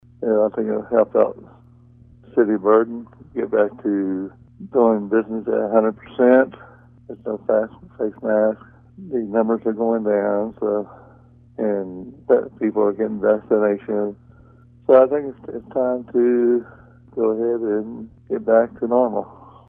Burton Mayor David Zajicek